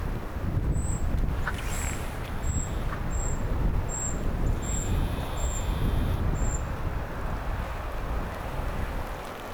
tuolla tavoin siritti toinen linnuista
tuollainen_sirityssarja_toisella_niista_puukiipijoista.mp3